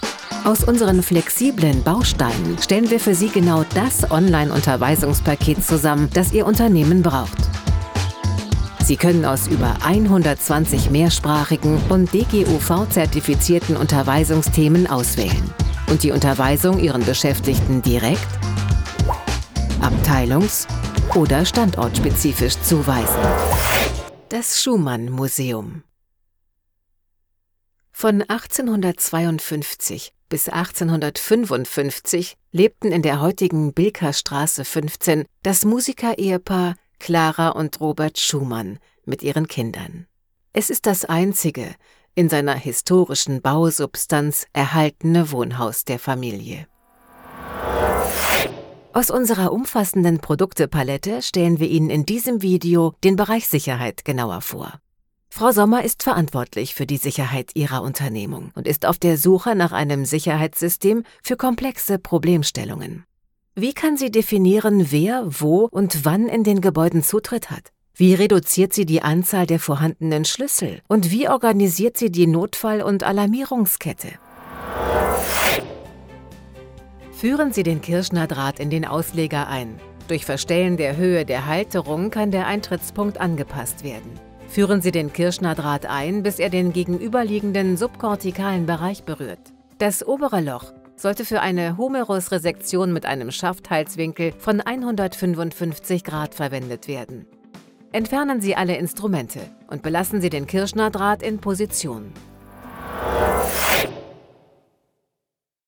Profonde, Naturelle, Polyvalente, Chaude, Corporative
Corporate
Elle dispose d’un studio professionnel à domicile et d’une solide expérience avec de grandes marques.